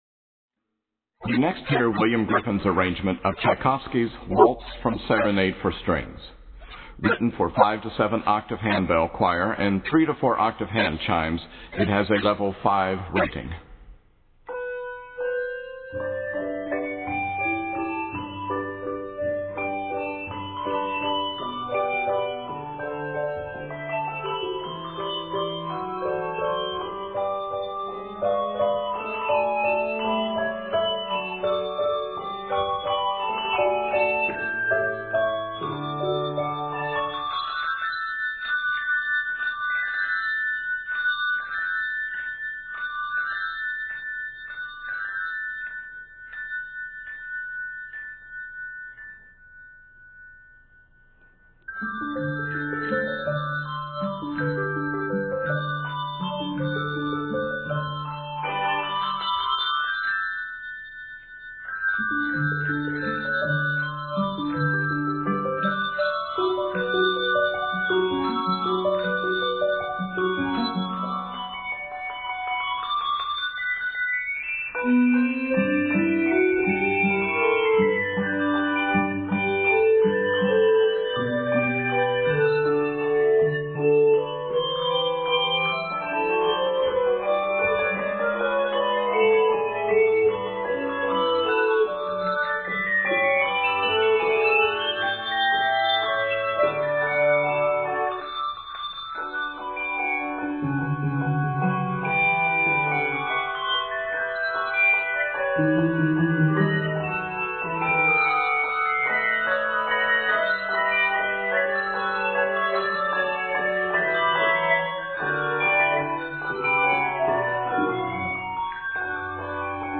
Octaves: 5-7